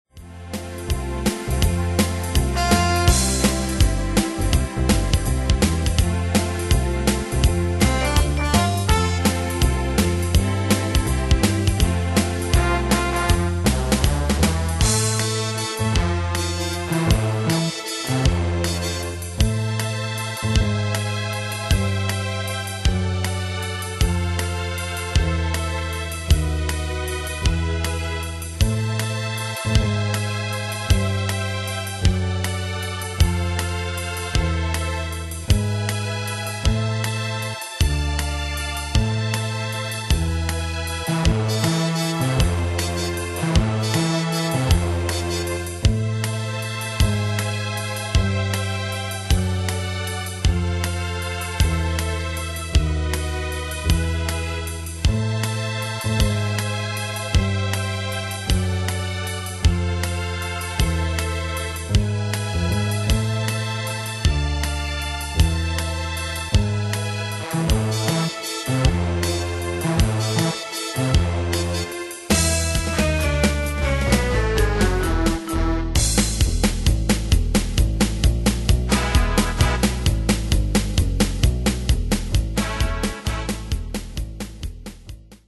Style: Enfants Ane/Year: 1964 Tempo: 165 Durée/Time: 4.38
Pro Backing Tracks